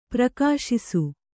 ♪ prakāśisu